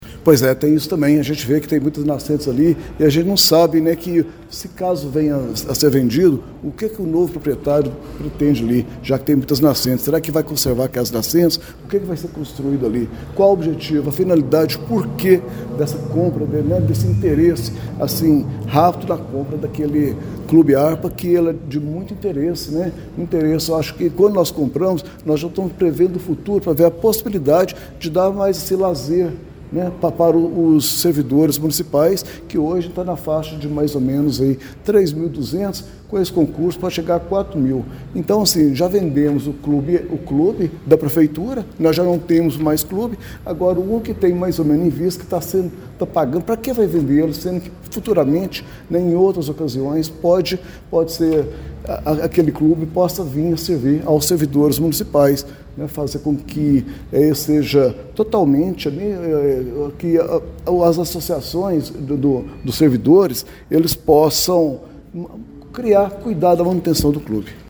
Durante a sessão realizada nesta terça-feira (07), o Projeto de Lei Ordinária nº 15/2026, que autoriza a prefeitura a vender o imóvel do extinto Clube Arpa, teve sua tramitação interrompida após um pedido de vista, revelando profundas divergências entre os parlamentares sobre o destino da área.